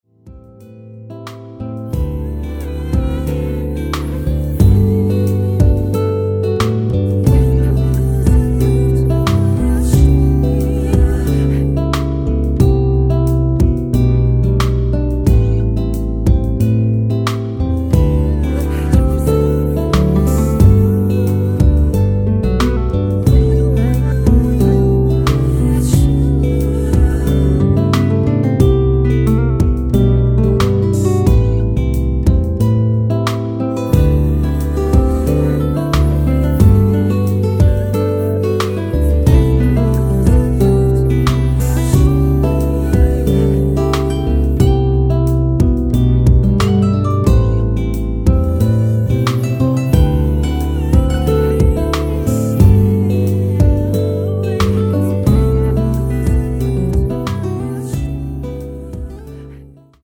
Am
앞부분30초, 뒷부분30초씩 편집해서 올려 드리고 있습니다.